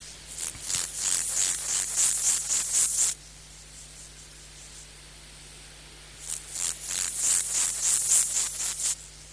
Акустические сигналы: одиночный самец, Монгольская Народная Республика, Убсу-Нурский аймак, хребет Хан-Хухий, запись
Температура записи 28-30° С.